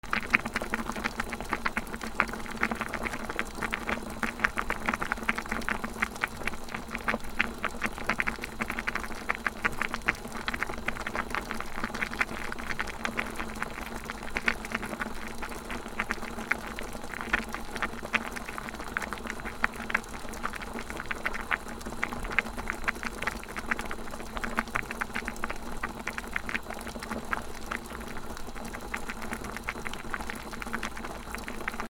なべ 沸騰
『グツグツ』